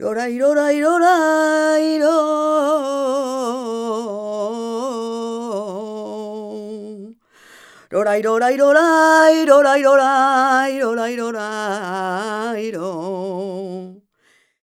46b02voc-g#.aif